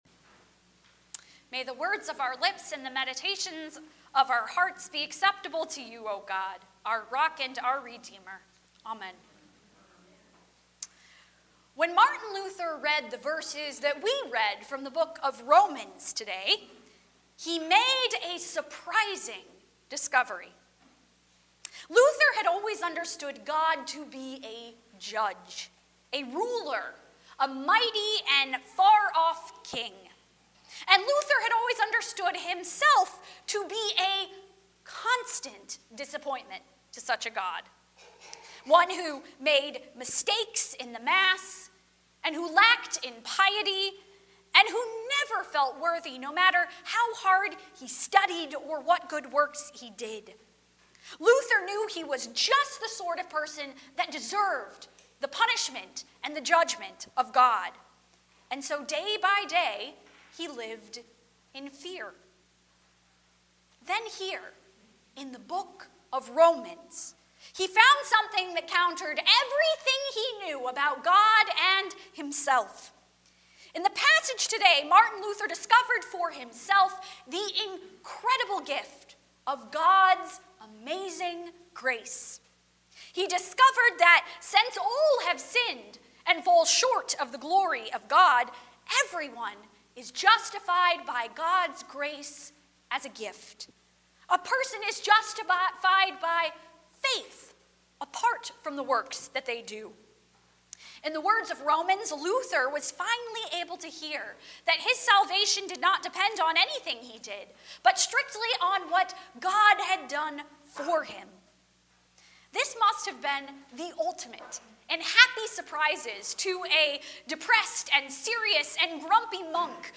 Service Type: Sunday Morning 10:30